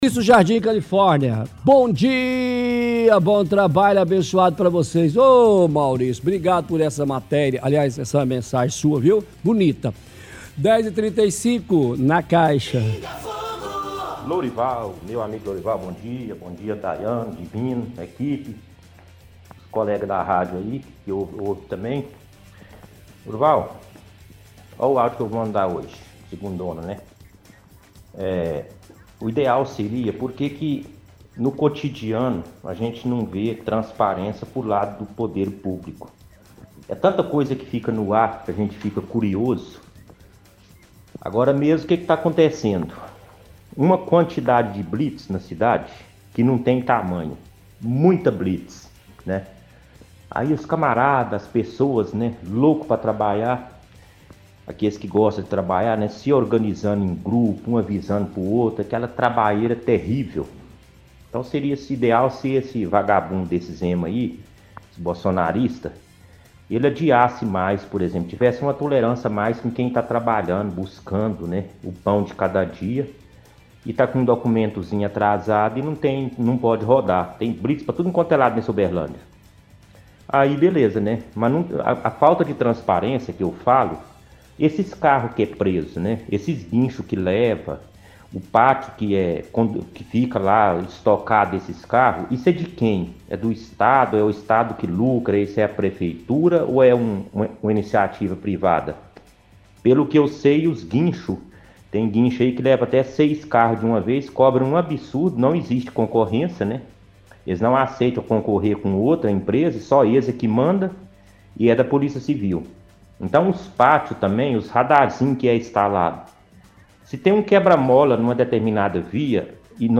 – Ouvinte reclama de falta de transparência do poder público.